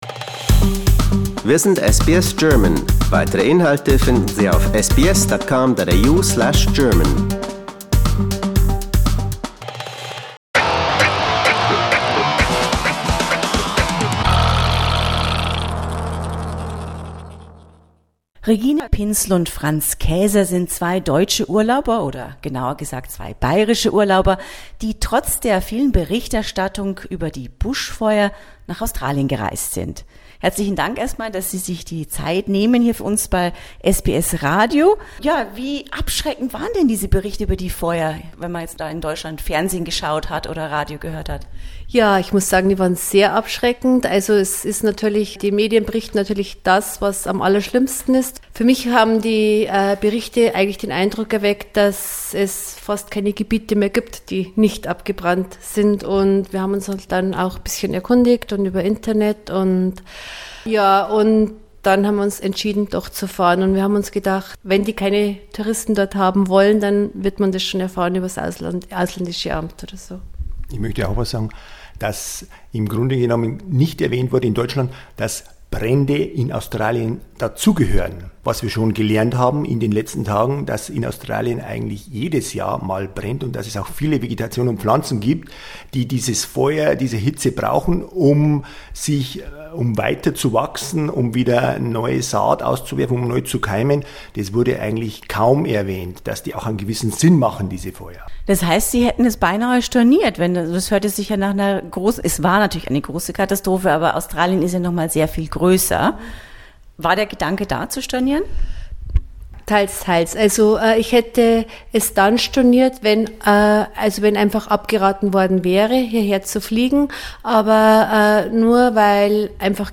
Sie interviewte zwei Urlauber, die gerade aus Deutschland ankamen und nun genau die Strecke zwischen Melbourne und Sydney abfahren werden, in der es gebrannt hat.